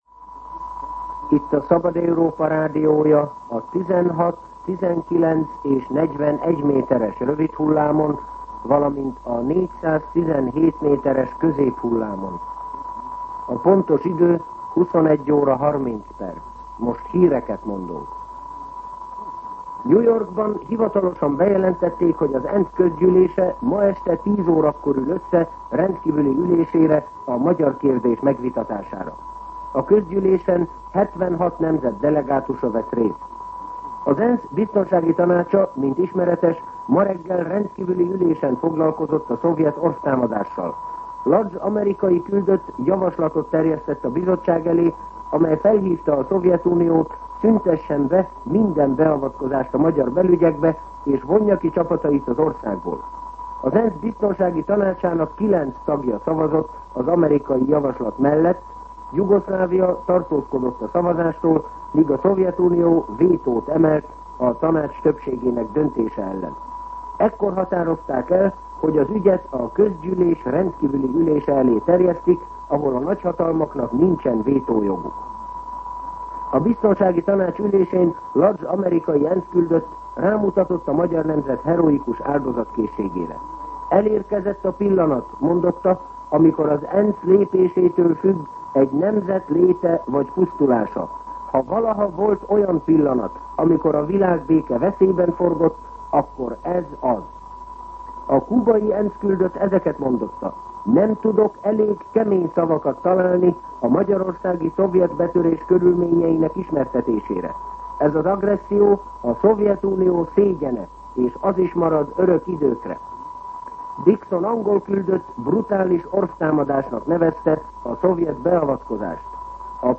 21:30 óra. Hírszolgálat